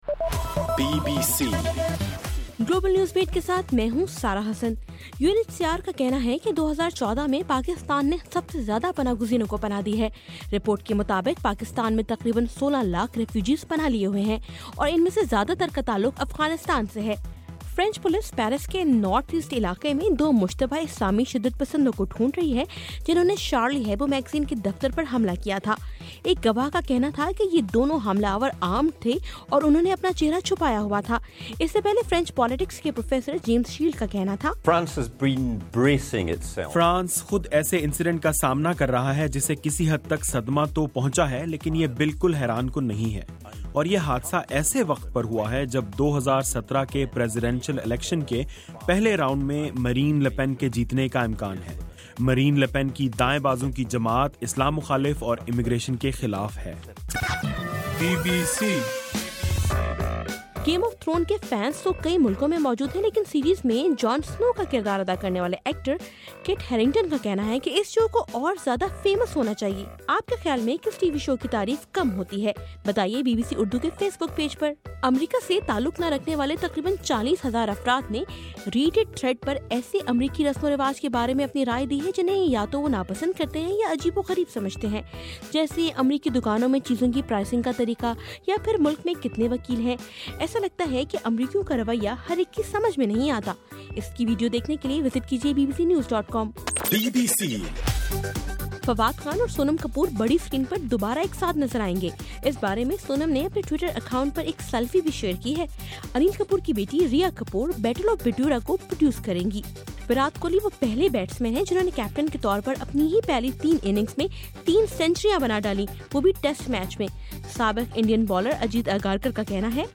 جنوری 9: صبح 1 بجے کا گلوبل نیوز بیٹ بُلیٹن